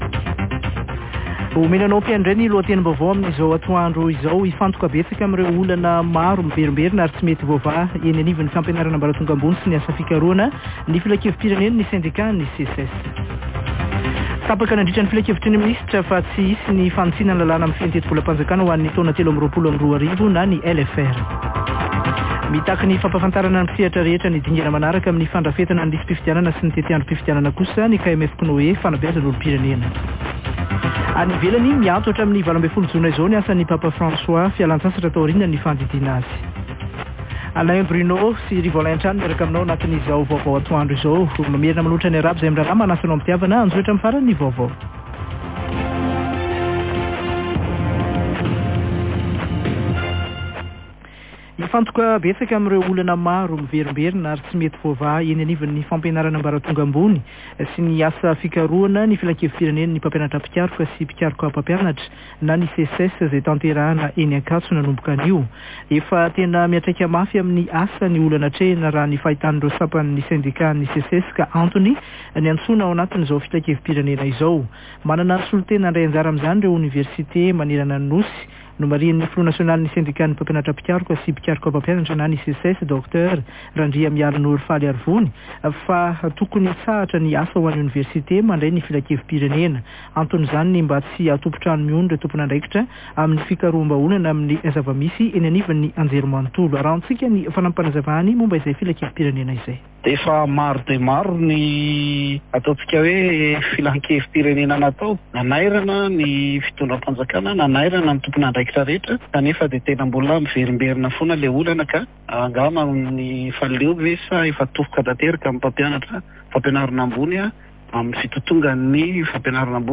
[Vaovao antoandro] Alakamisy 8 jona 2023